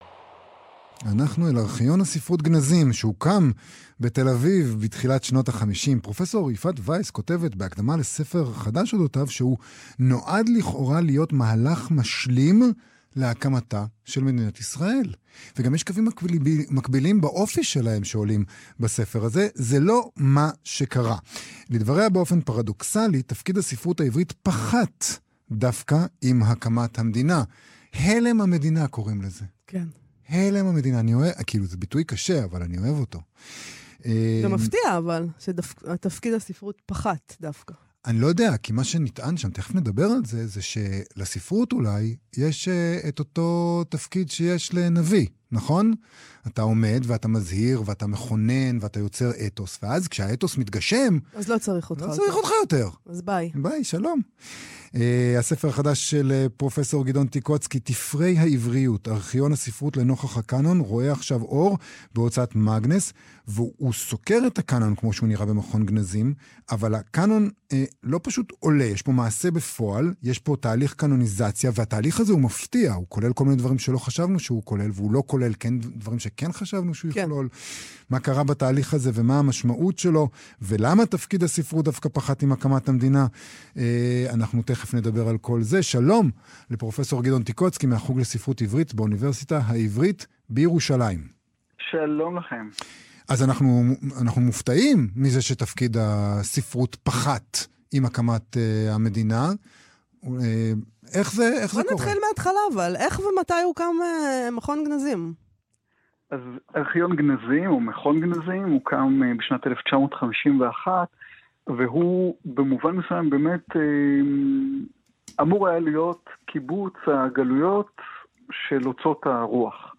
ריאיון